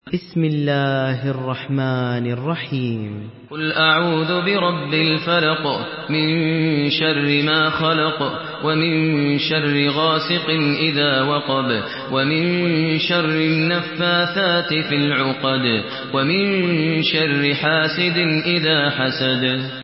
سورة الفلق MP3 بصوت ماهر المعيقلي برواية حفص
مرتل